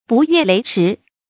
不越雷池 bù yuè léi shi
不越雷池发音
成语注音 ㄅㄨˋ ㄩㄝˋ ㄌㄟˊ ㄔㄧˊ